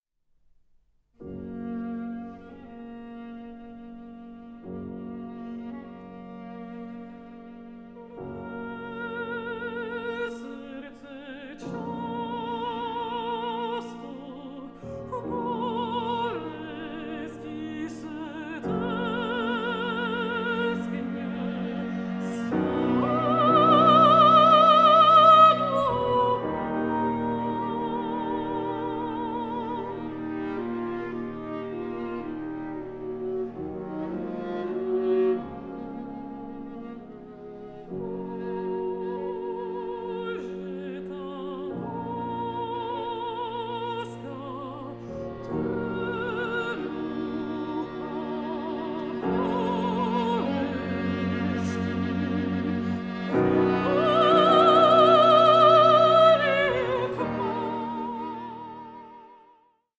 AN EVENING OF INTIMATE SONGS AMONG FRIENDS
mezzo-soprano